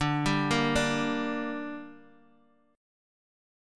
Dsus4#5 chord